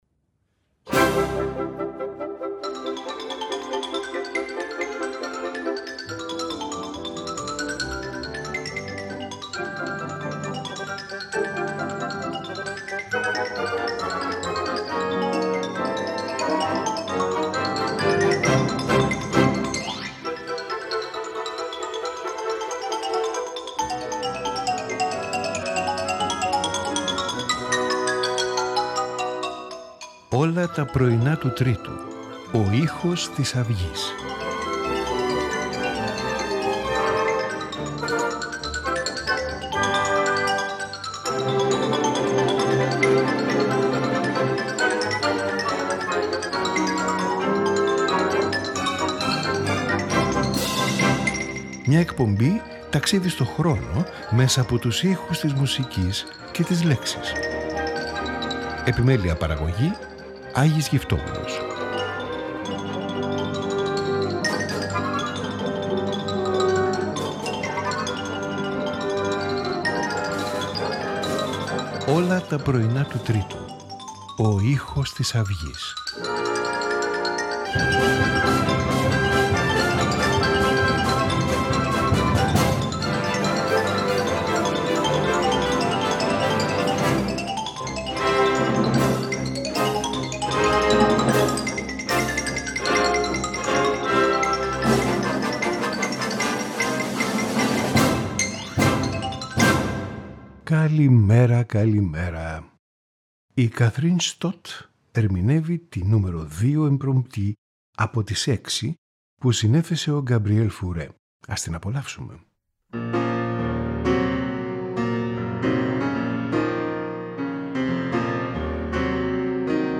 String Quartet in G minor
Concerto for Two Mandolins in G major
Symphony No.5 in C minor